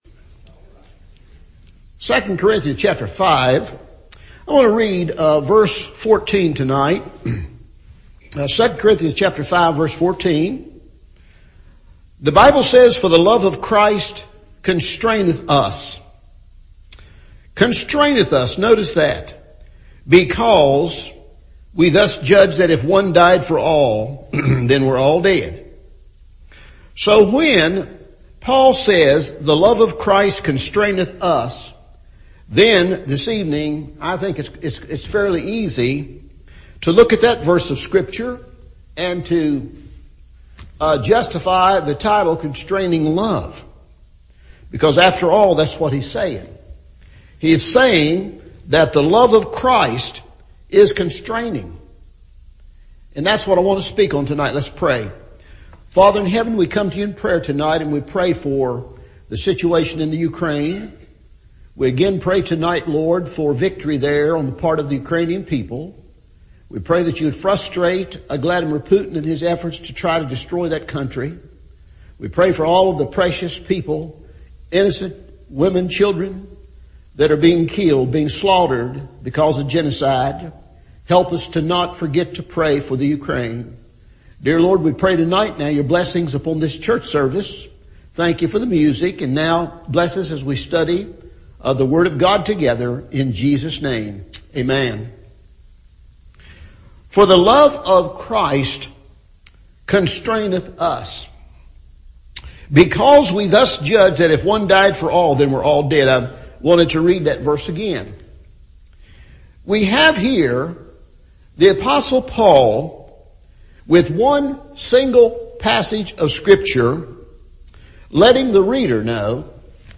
Constraining Love – Evening Service